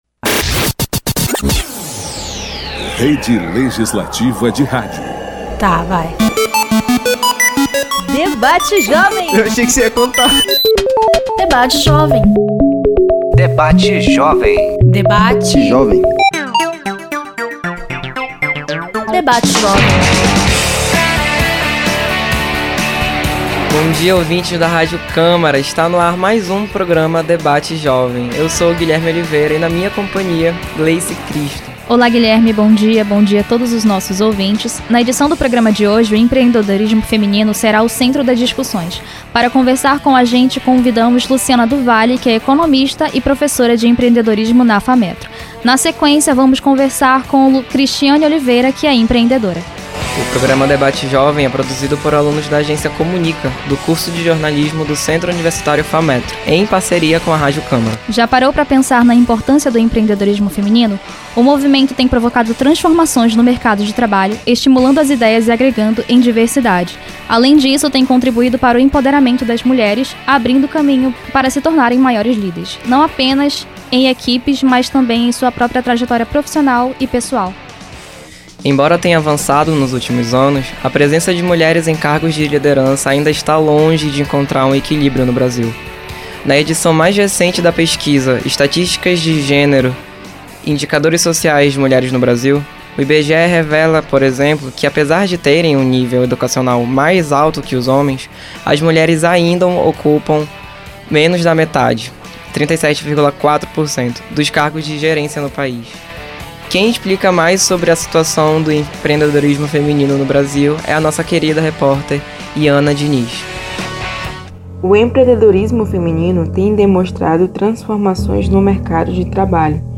Debate Jovem é um programa realizado pela Agência Experimental de Comunicação do curso de Jornalismo do Centro Universitário Fametro em parceria com Radio Câmara a Rádio Cidadã de Manaus, 105,5 FM.